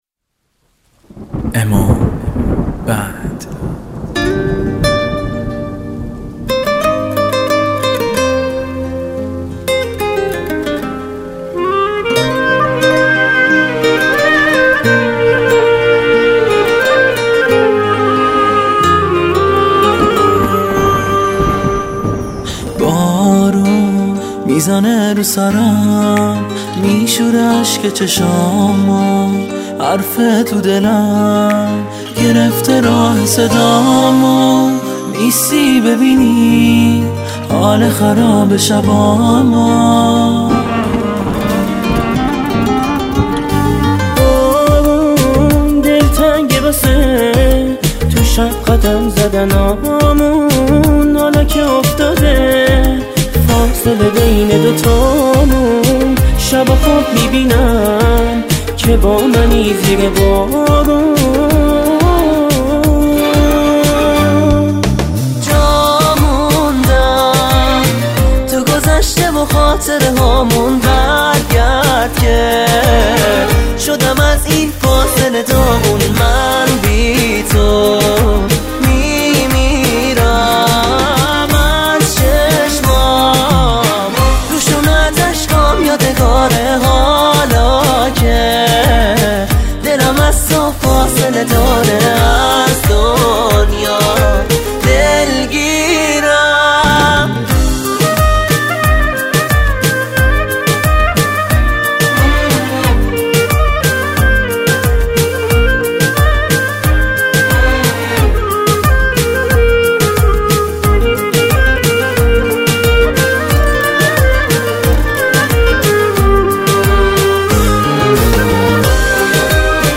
ویولن
گیتار
کلارینت